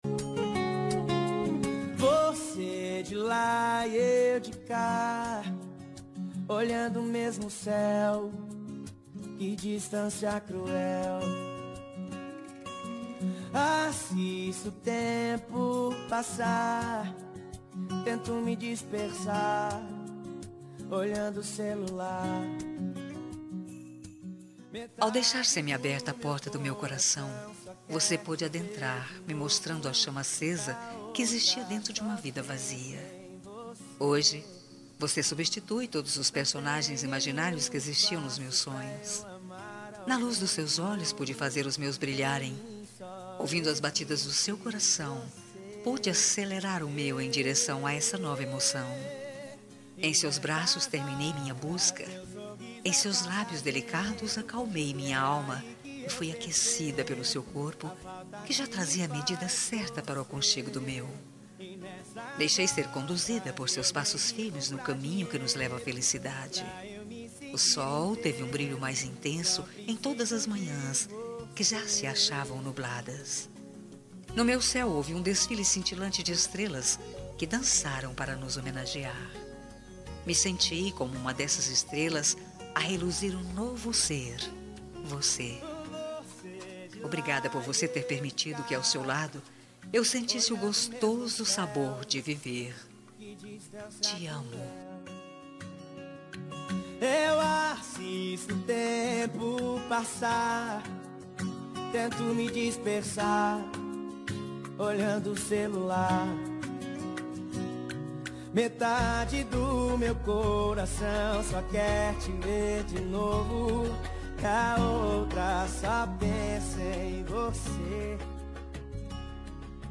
Telemensagem Romântica – Suave – Voz Feminina – Cód: 7854